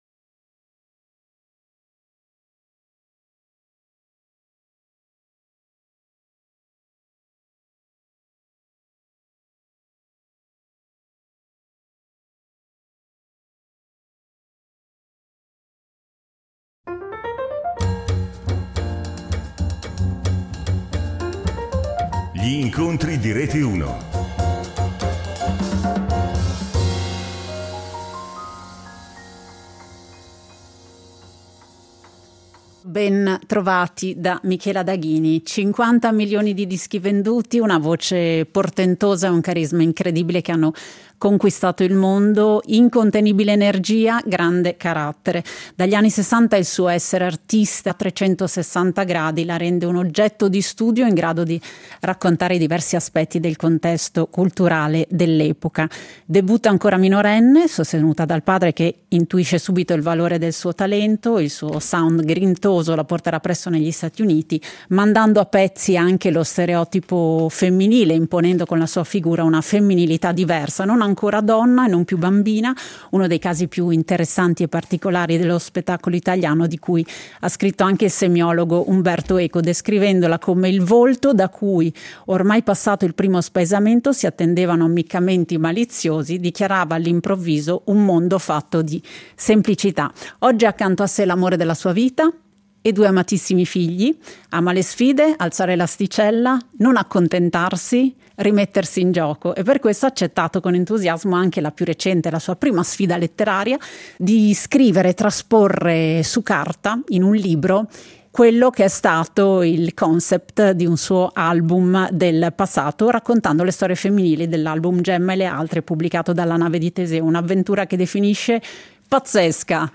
Gli Incontri di Rete Uno